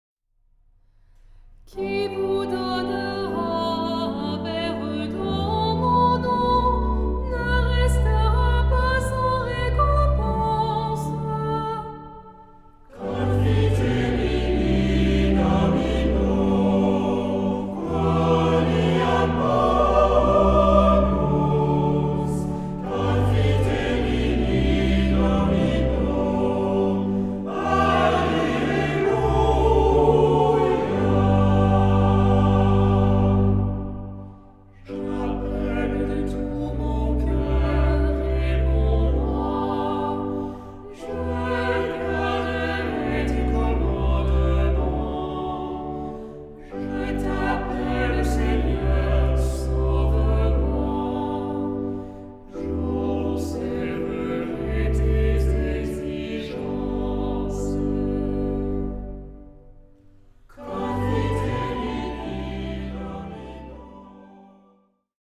Genre-Style-Forme : Tropaire ; Psalmodie
Caractère de la pièce : recueilli
Type de choeur : SATB  (4 voix mixtes )
Instruments : Orgue (1) ; Instrument mélodique (1)
Tonalité : sol majeur